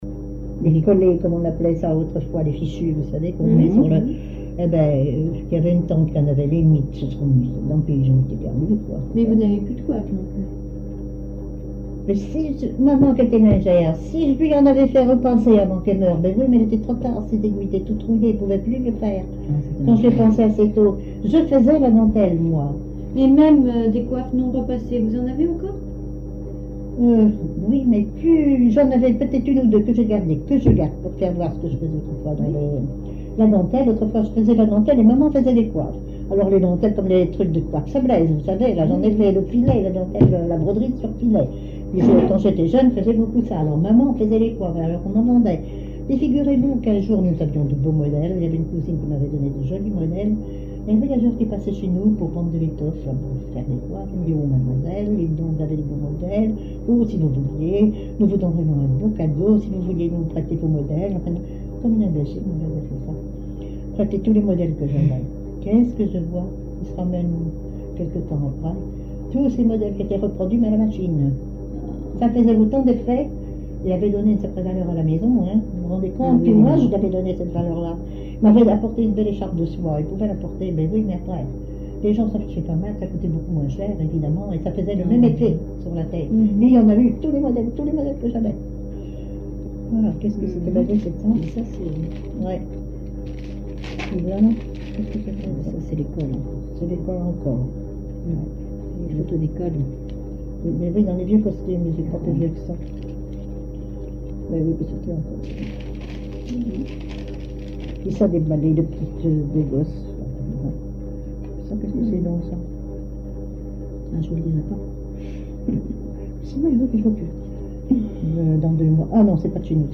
Enquête Tap Dou Païe et Sounurs, sections d'Arexcpo en Vendée
Catégorie Témoignage